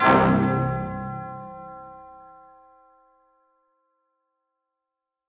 C (Juicy Hit).wav